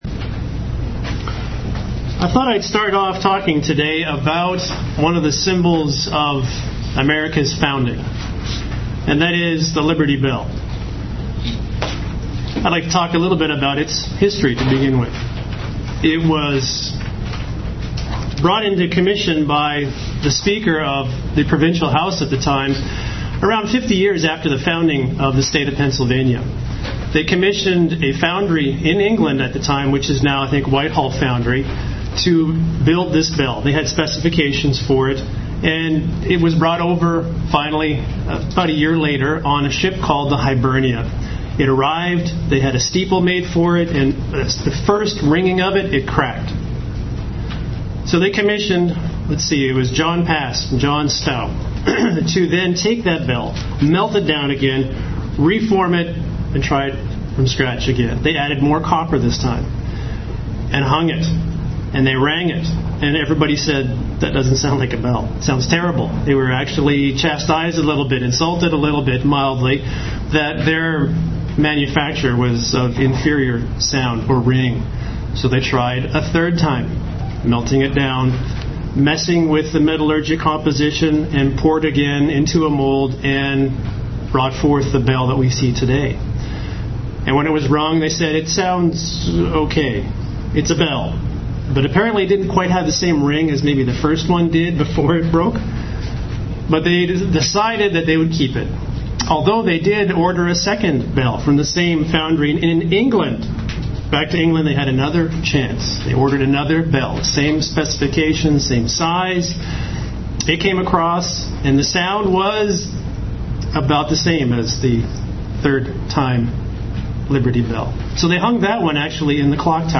Given in Cincinnati North, OH
UCG Sermon Studying the bible?